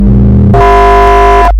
凉爽的警报器
描述：我用大胆生成的一个很酷的警报警报器。
Tag: 紧急 报警 警报 警笛